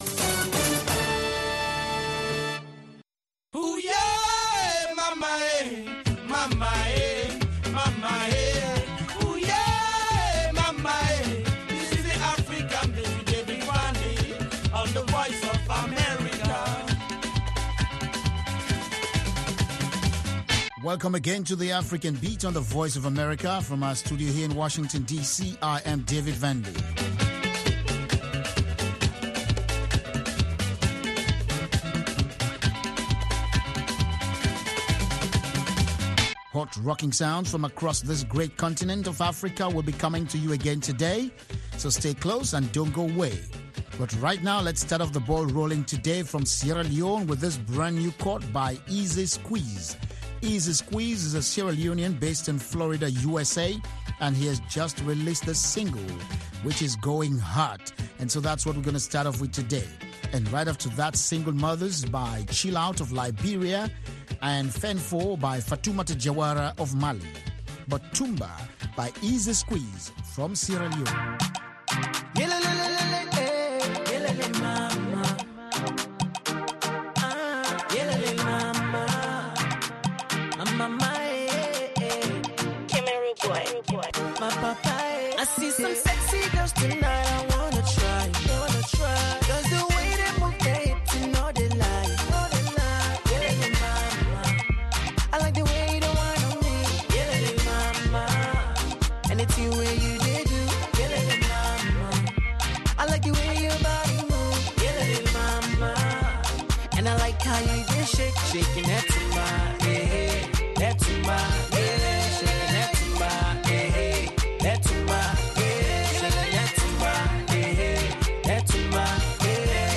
From Benga to Juju, Hip Life to Bongo Flava, Bubu to Soukous and more